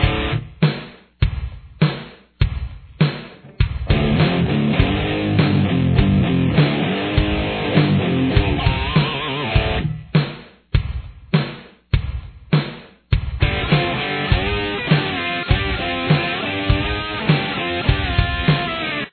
This riff is in drop D tuning.